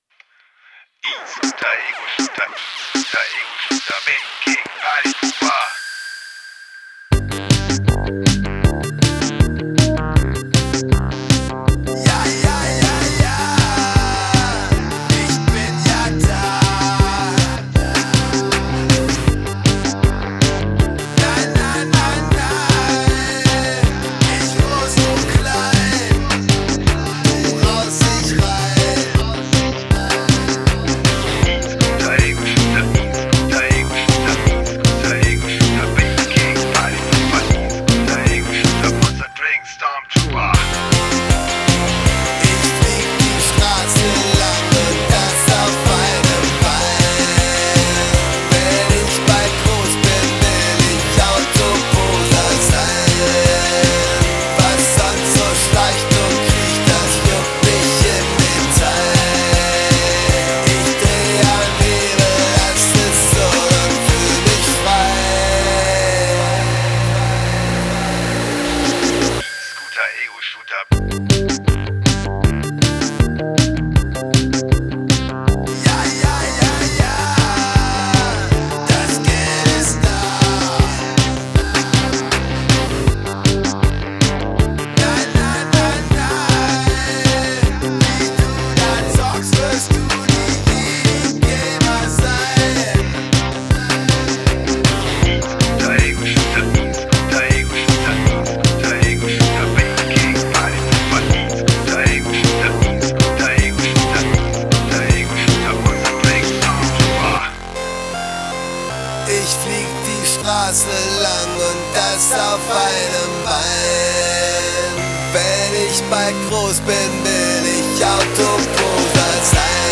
LoFi-Elektro-NDW-Plastik-Punk-Daddelautomaten-Pop